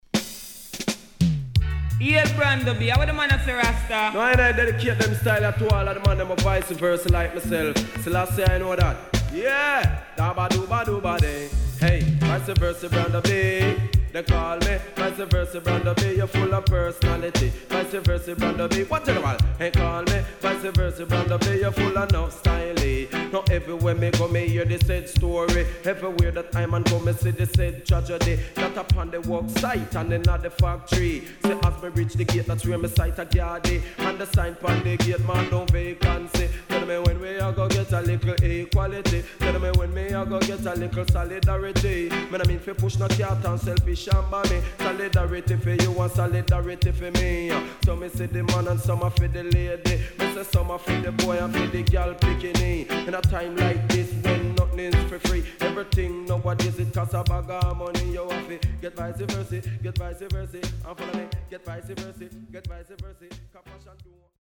HOME > Back Order [DANCEHALL DISCO45]
SIDE A:少しチリノイズ入ります。